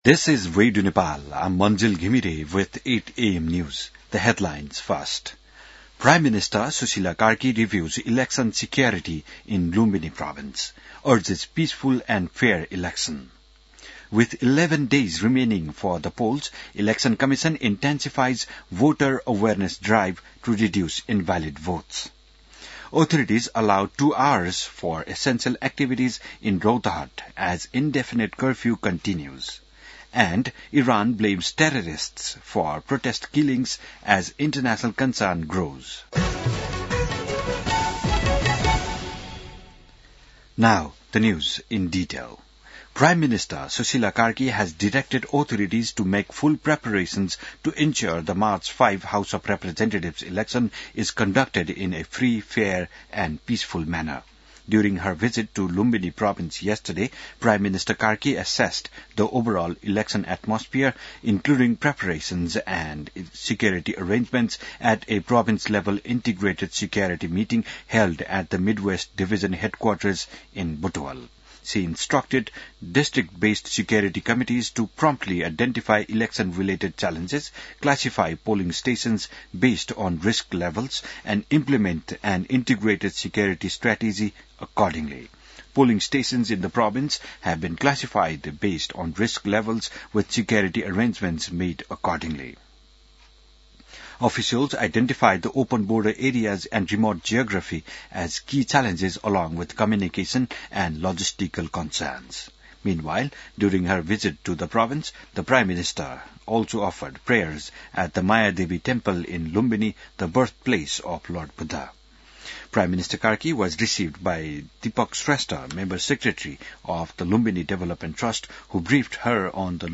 बिहान ८ बजेको अङ्ग्रेजी समाचार : १० फागुन , २०८२